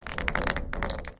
grenade_roles_in1.ogg